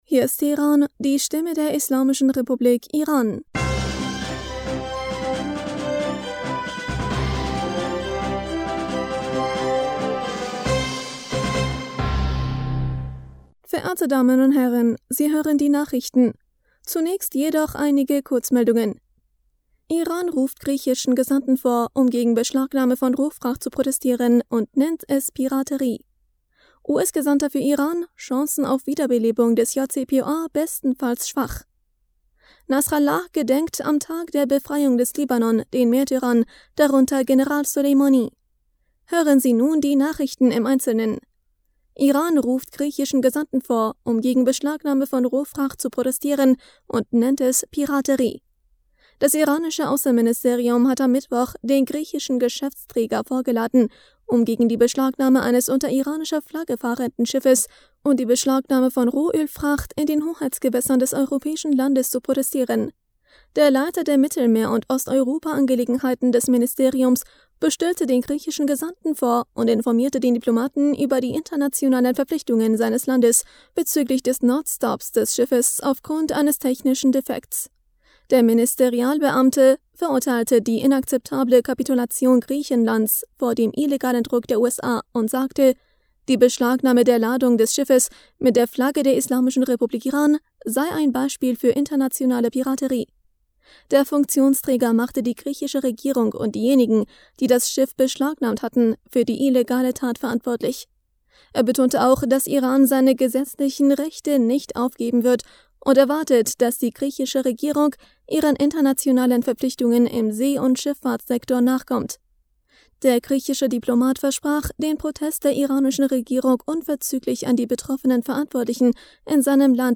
Nachrichten vom 26. Mai 2022